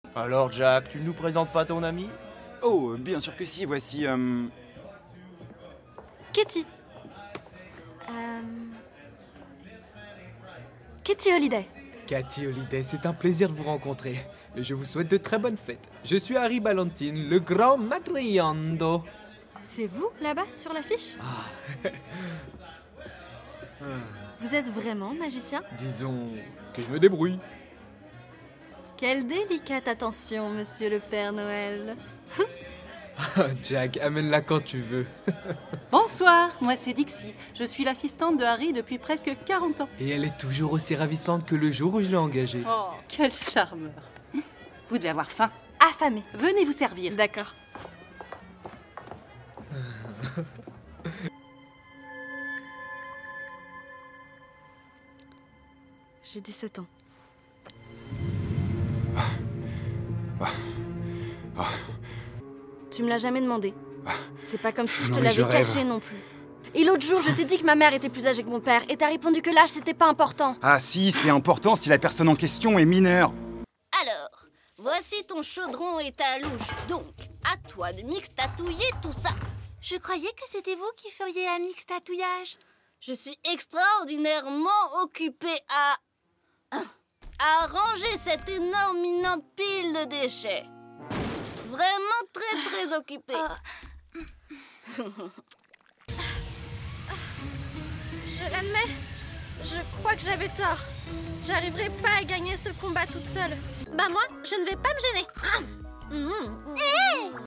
Voix off
Bande démo son
- Mezzo-soprano